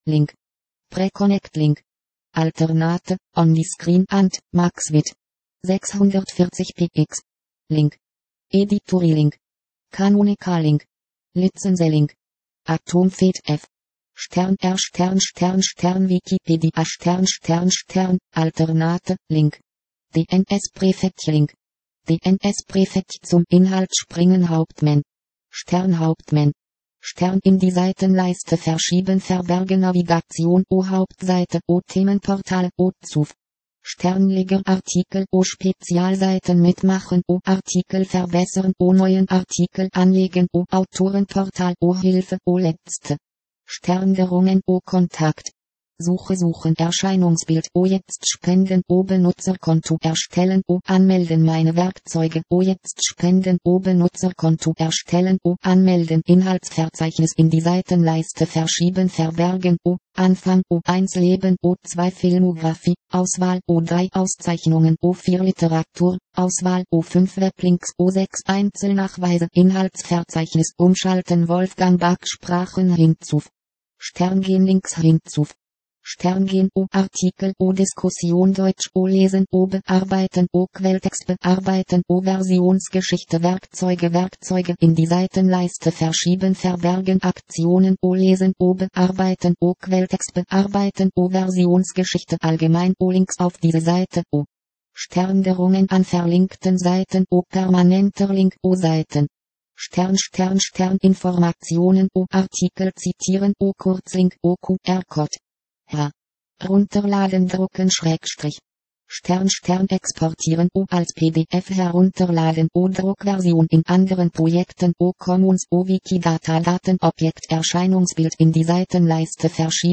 wolfgang_back.mp3 generiert aus Wikipedia